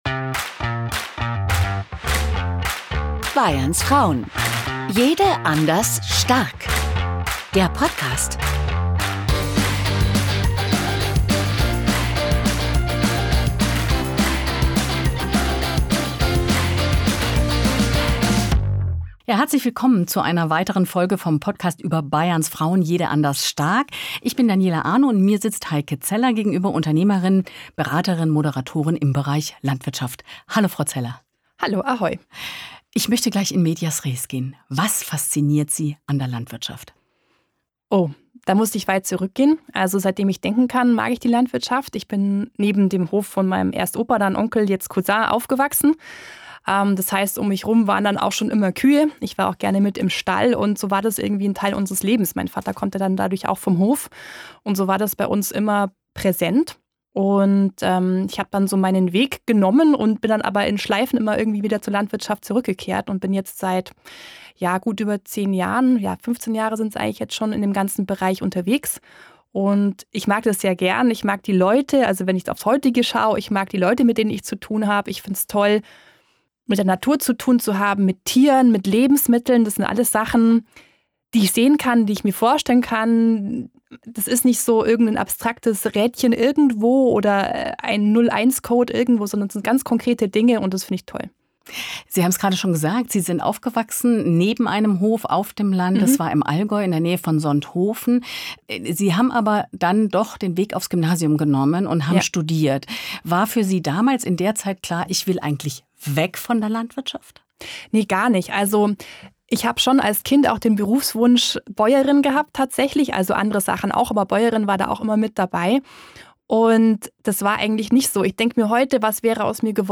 Porträt